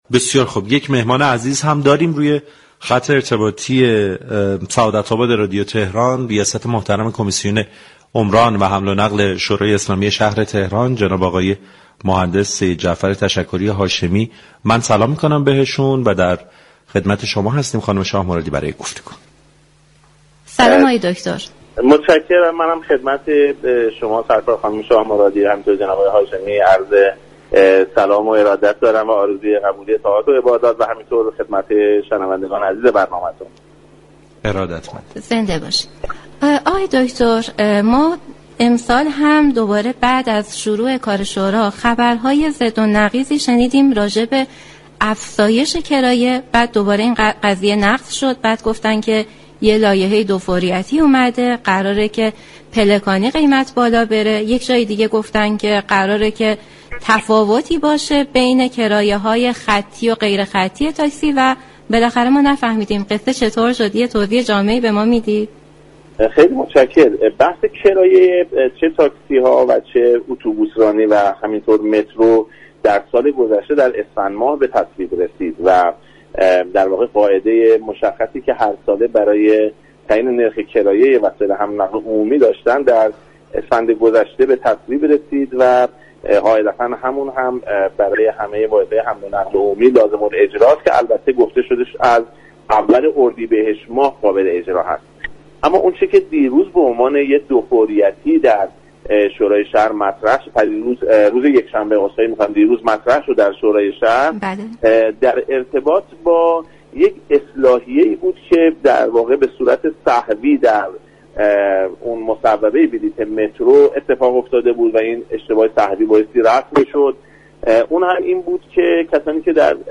به گزارش پایگاه اطلاع رسانی رادیو تهران، سیدجعفر تشكری هاشمی رئیس كمیسیون عمران و حمل و نقل شورای اسلامی شهر تهران در گفت و گو با برنامه سعادت آباد رادیو تهران در خصوص افزایش كرایه حمل و نقل عمومی در تهران گفت: این بحث اسفندماه سال گذشته بر اساس قاعده كلی كه هرساله وجود دارد به تصویب رسید و از اول اردیبهشت‌ماه برای تمامی وسایل حمل و نقل عمومی اعم از اتوبوس، مترو و تاكسی لازم الاجرا است.